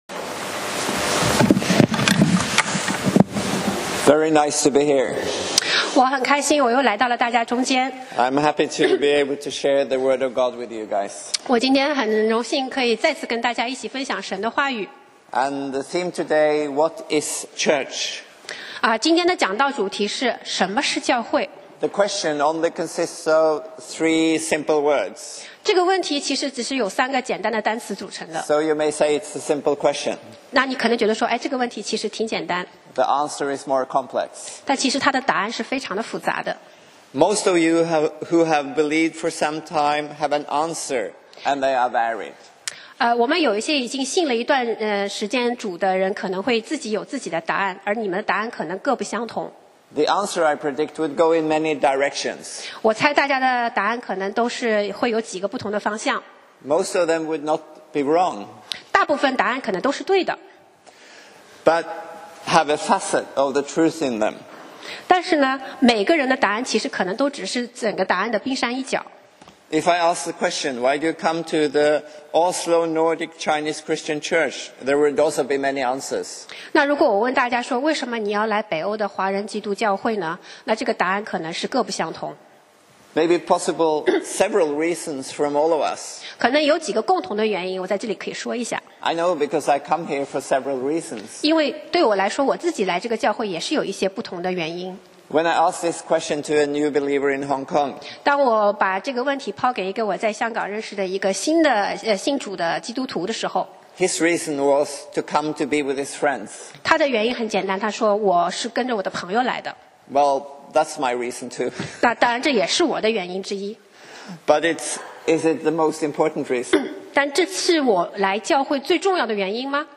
講道 Sermon 題目 Topic：What is Church 經文 Verses：哥林多前书 12:12-27 12就如身子是一个，却有许多肢体。